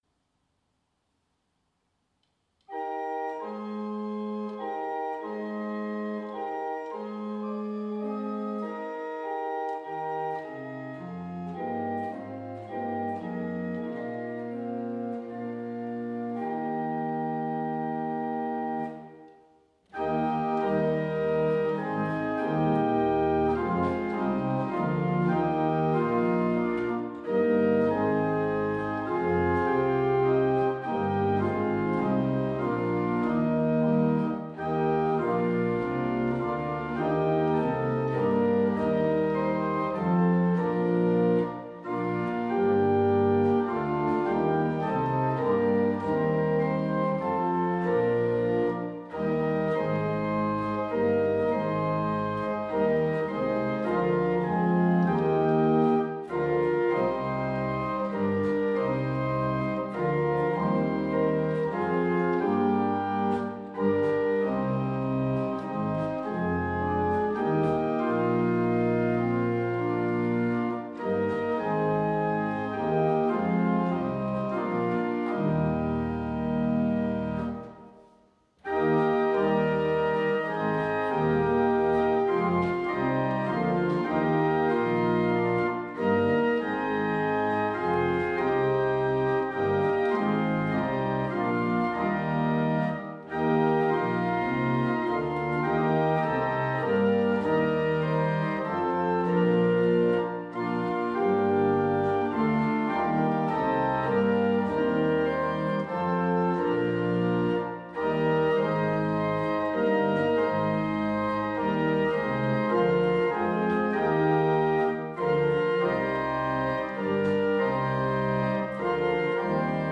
Choräle für Advent und Weihnachtszeit an Orgeln des Kirchenkreises